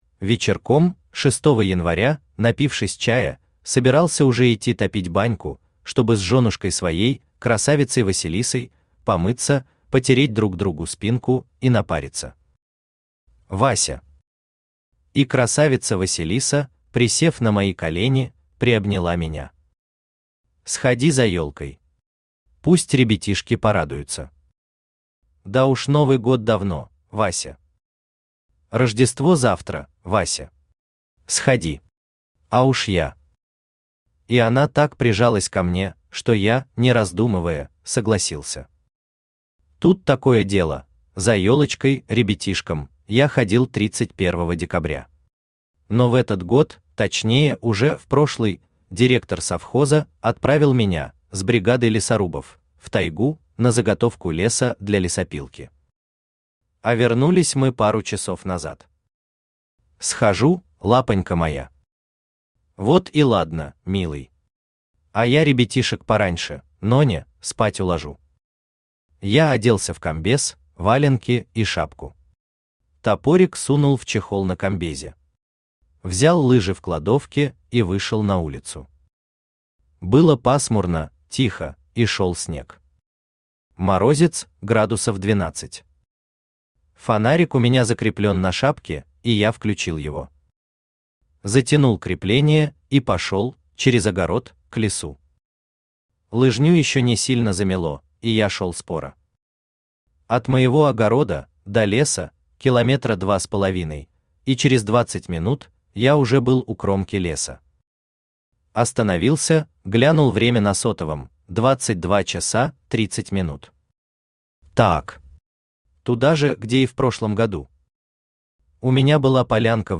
Аудиокнига Кипарисовый крестик | Библиотека аудиокниг
Aудиокнига Кипарисовый крестик Автор Георгий Шевцов Читает аудиокнигу Авточтец ЛитРес.